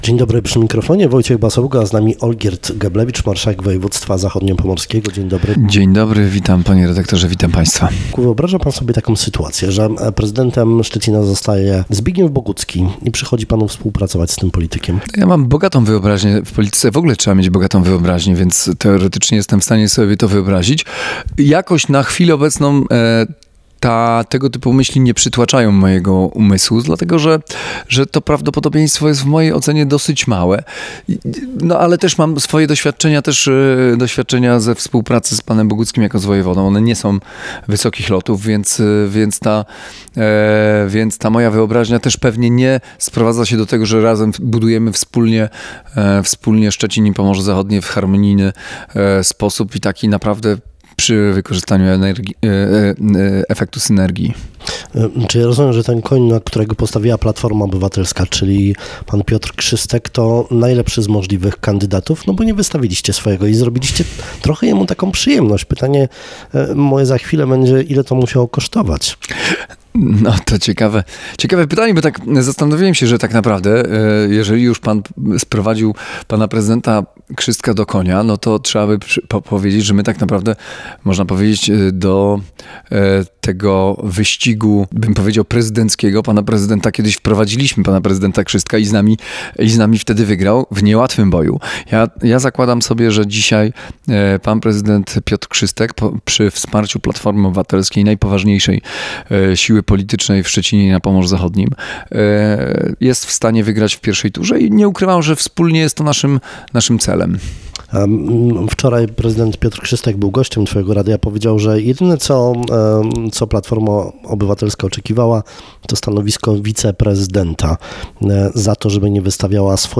Olgierd Geblewicz był w piątek gościem Rozmowy Dnia w Twoim Radiu. Samorządowiec pytany o nadchodzące wybory i możliwość współpracy na szczeblu lokalnym z samorządowcami Prawa i Sprawiedliwości odpowiedział, że to formacja antysamorządowa.